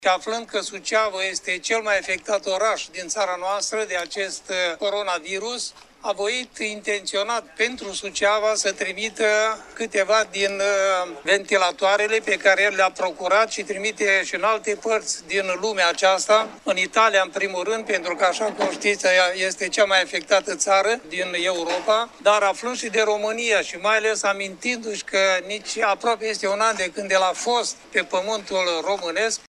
Episcopul romano-catolic de Iasi-monseniorul Iosif PAULET a subliniat ca Sfantul Parinte a fost impresionat de suferinta bolnavilor de COVID 19 din Suceava: